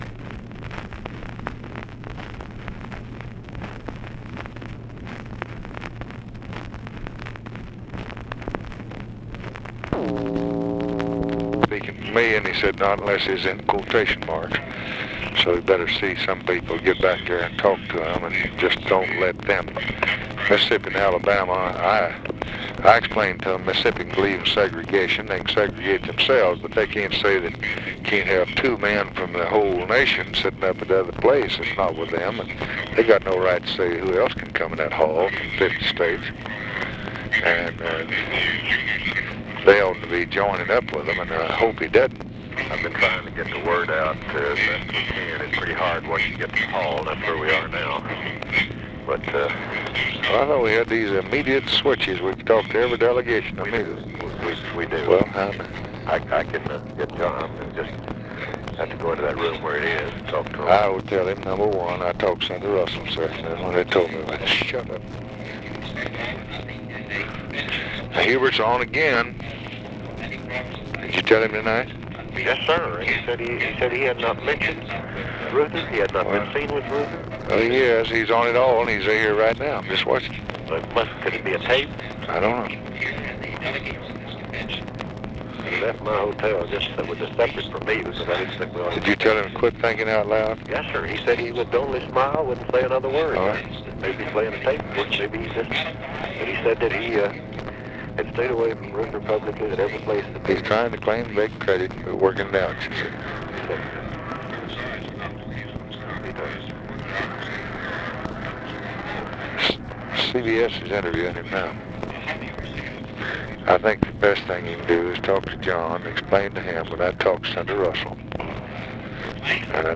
Conversation with WALTER JENKINS and JOHN CONNALLY, August 26, 1964
Secret White House Tapes